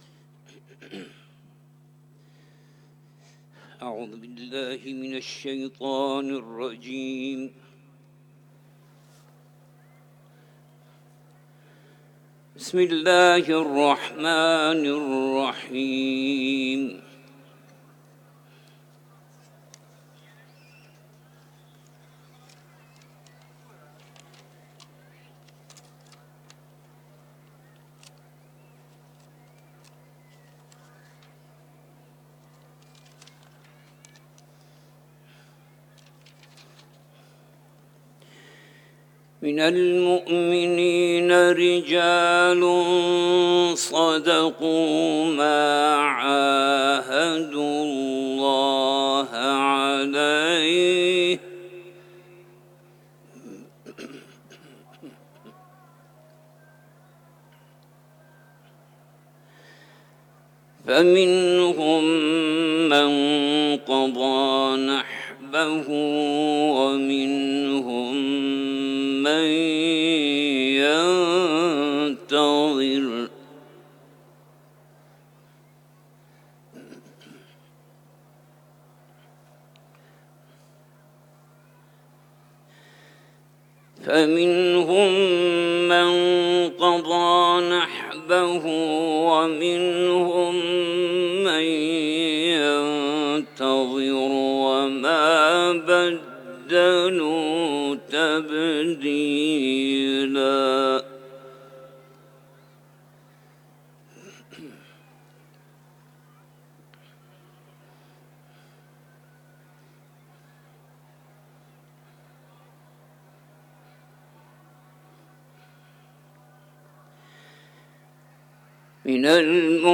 تلاوت
سوره احزاب ، حرم مطهر رضوی